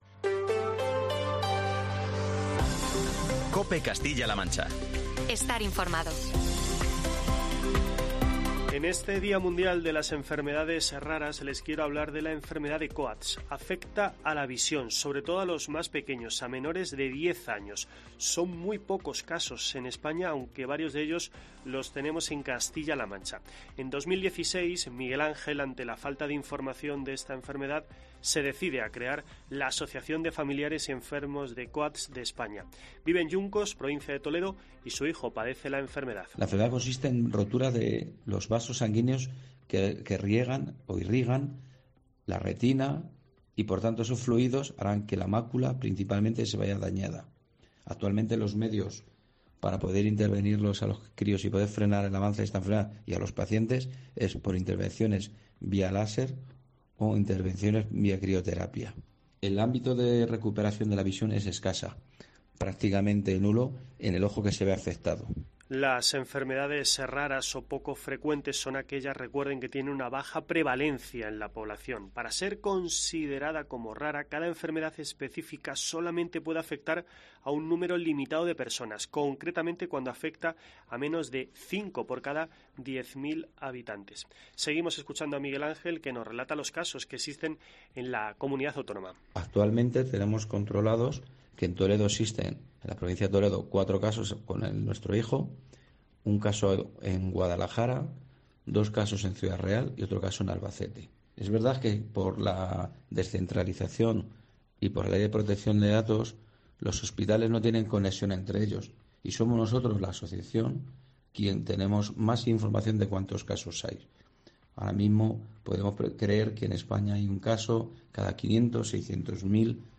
La Federación Española de Enfermedades Raras explica en COPE Castilla-La Mancha que dicho diagnóstico es fundamental para conseguir un acceso eficaz a tratamientos y terapia